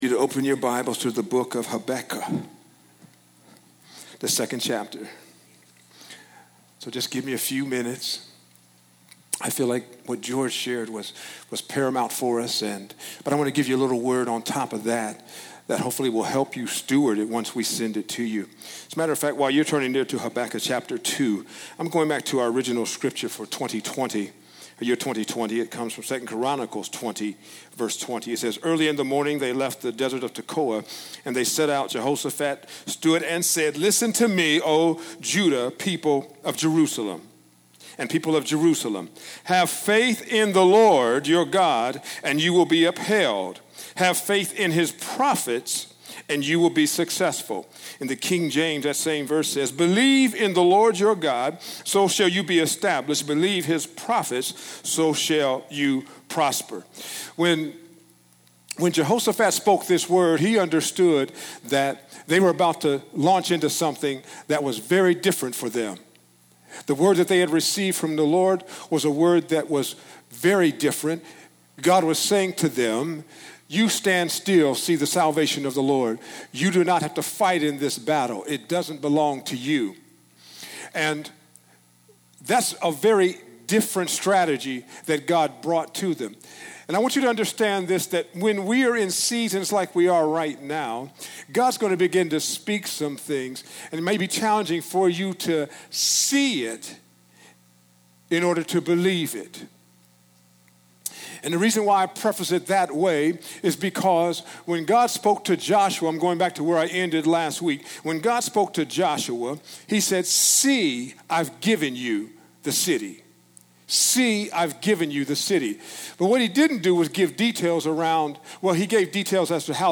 Category: Teachings